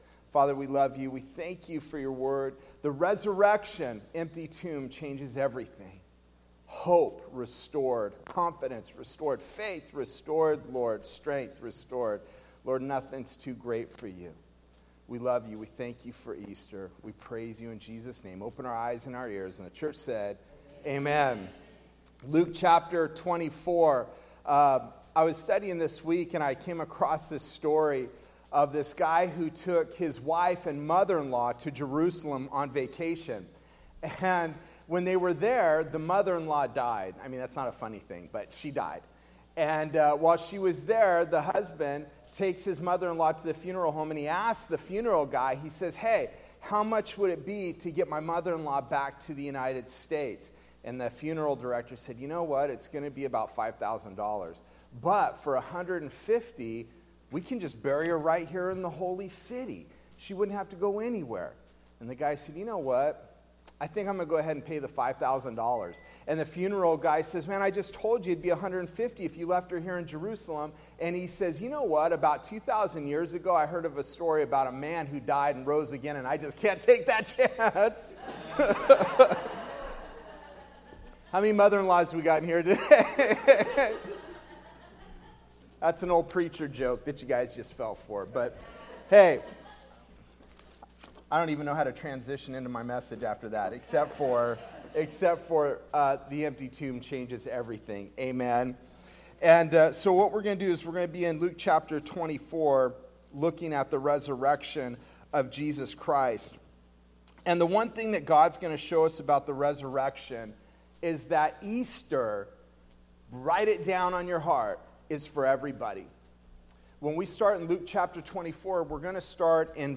Sermons Archive - Ark Bible Church